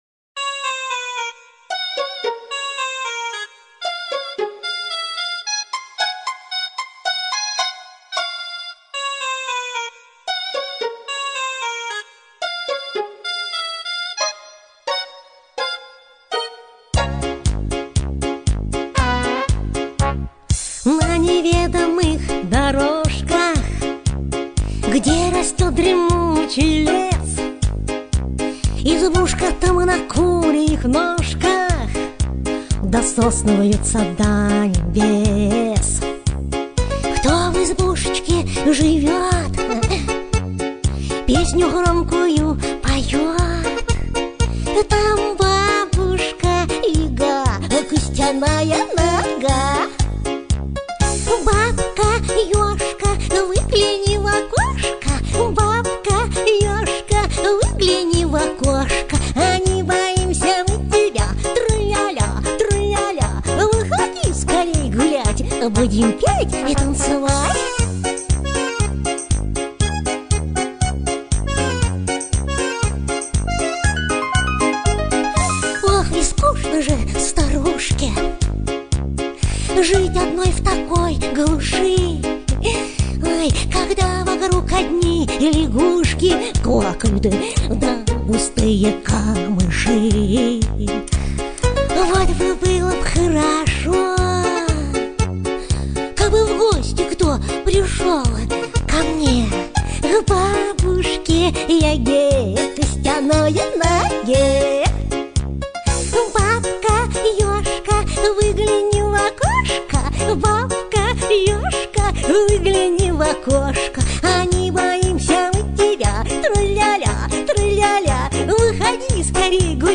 • Категория: Детские песни / Песни про бабушку